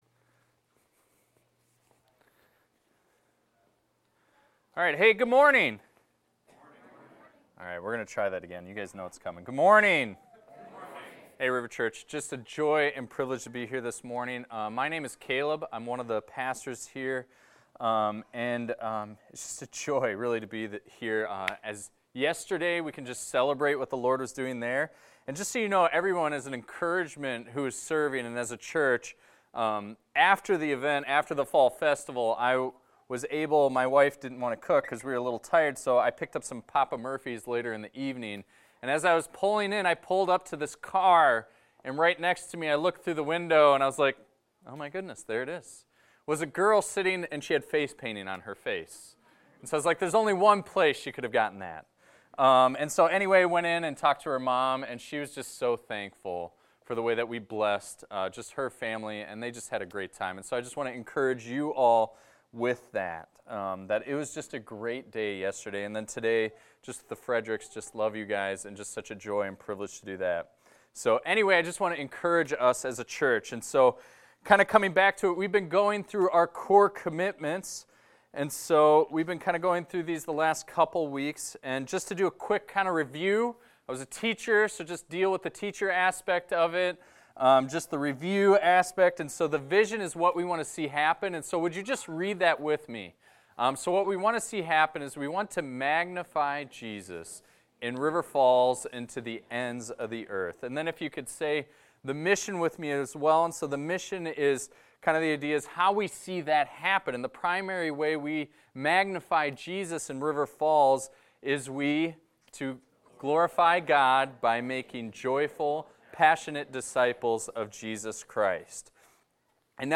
This is a recording of a sermon titled, "Grow to Know God Deeply."